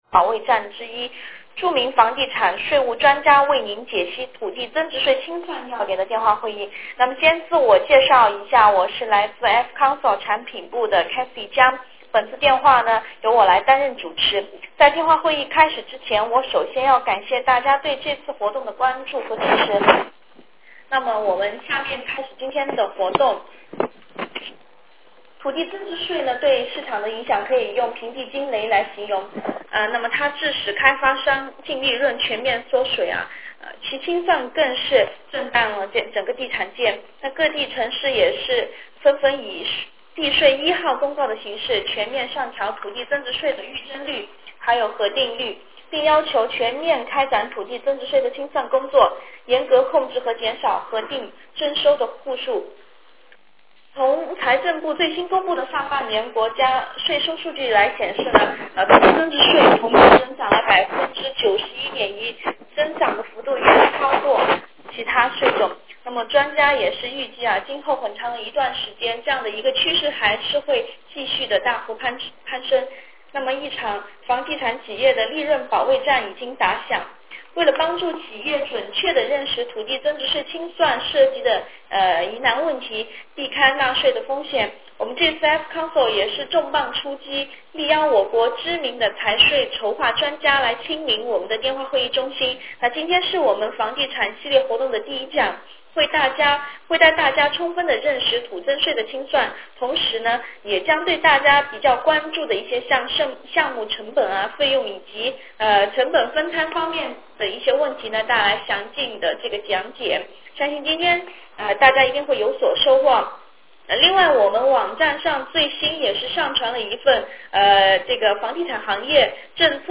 15:05 房地产企业土地增值税清算相关要点 --最新土地增值税政策发展趋势 --如何确定清算的条件，清算使用方法以及时间 --土增税准予扣除的项目成本和费用的确定 --清算项目成本分摊要点解析 16:40 互动答疑